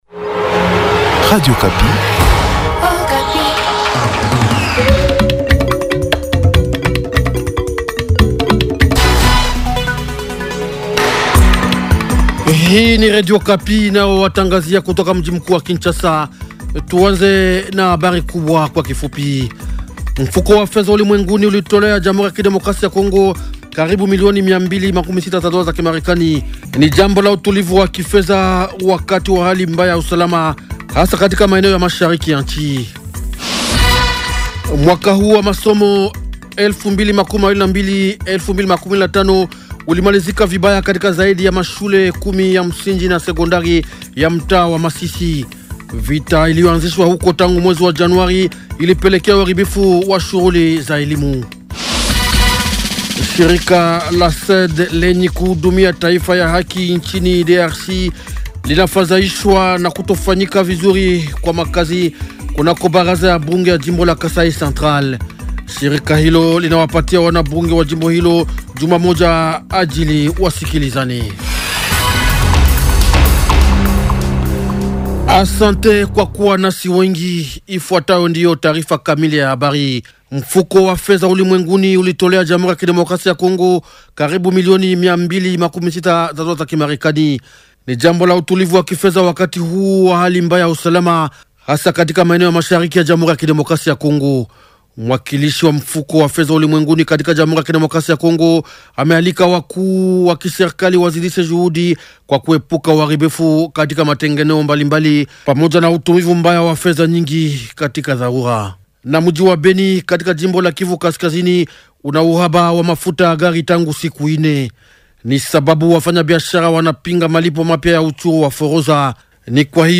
Journal Swahili